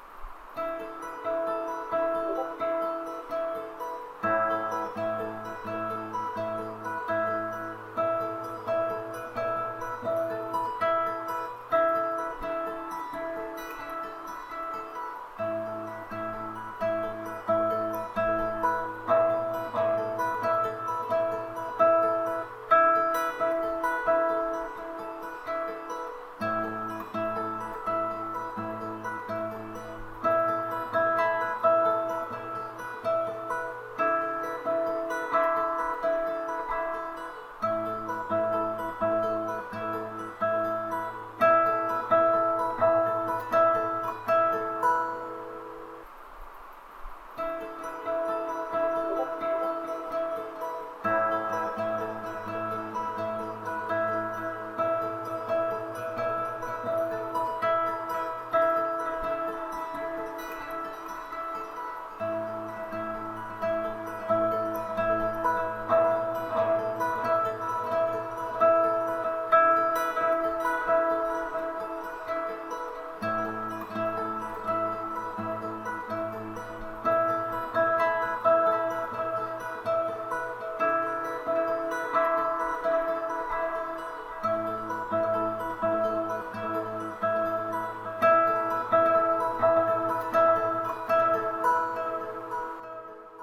I improvised the whole thing and can't really figure out how to do it again. It sounded nice to me though, and I recommend experimenting with it a little bit to add the right amount of echo, delay or reverb for you.